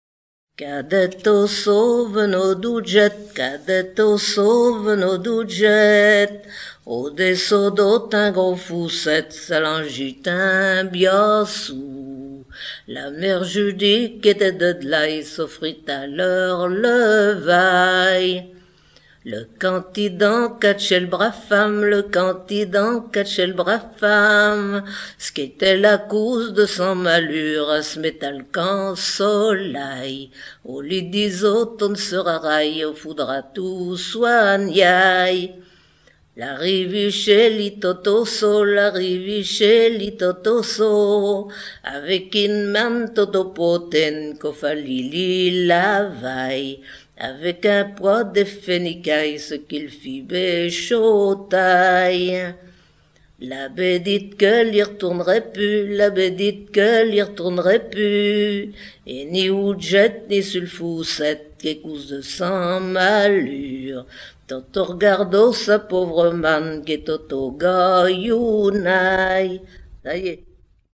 Noël
Pièce musicale éditée